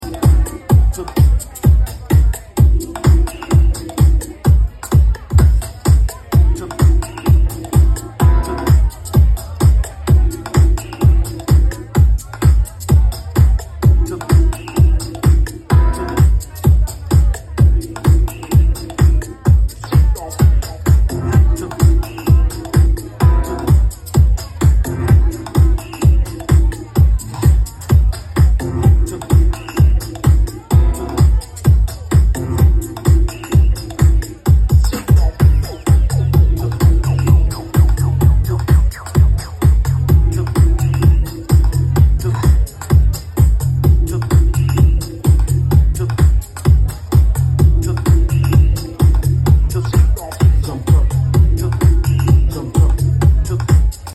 Dimensions Festival